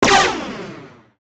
droideka_fire.wav